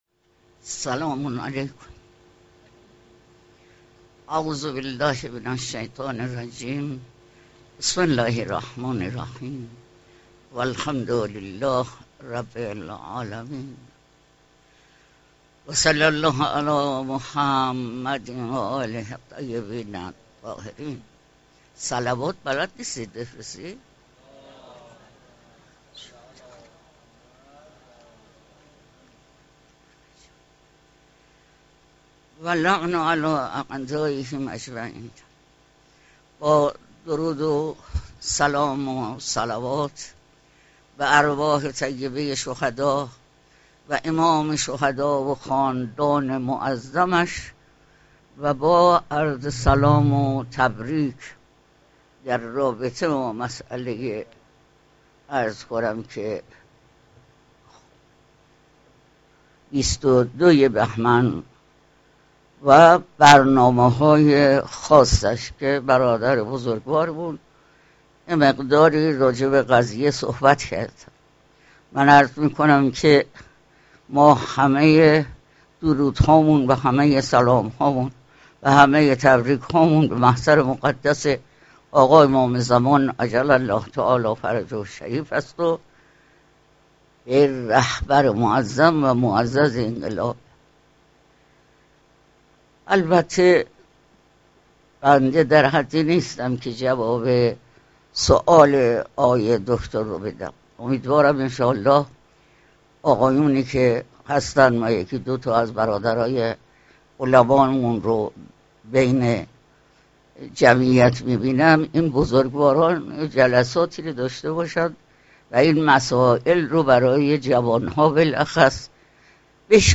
مراسم جشن انقلاب